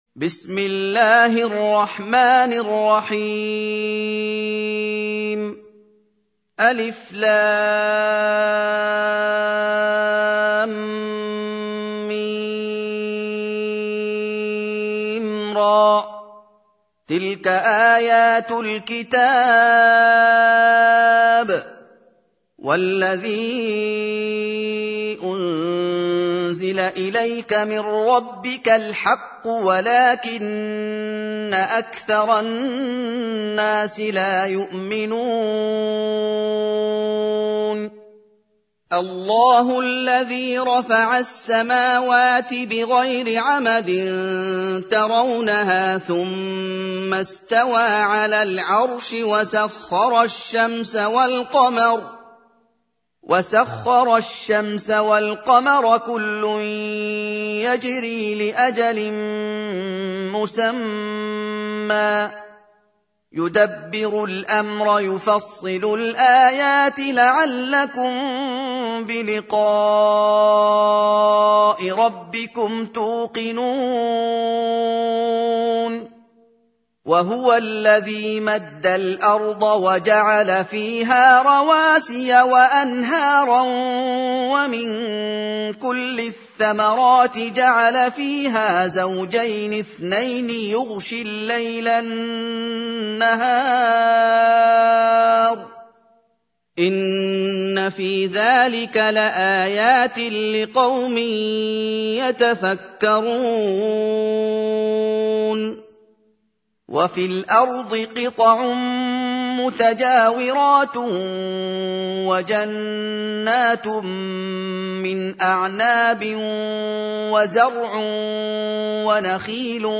سورة الرعد | القارئ محمد أيوب